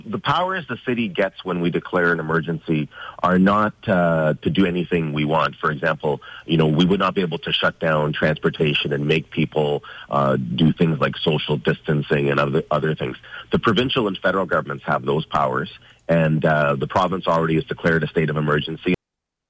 Speaking with the Mix Morning Crew, he said they’re also trying to ease burdens in other ways.